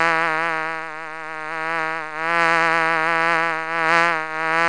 bee.mp3